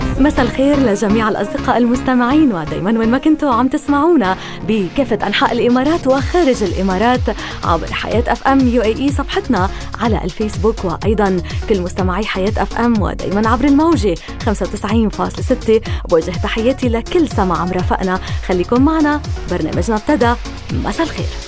Lübnan Arapçası Seslendirme
Kadın Ses